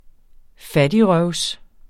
Udtale [ ˈfadiʁɶwsˌ- ]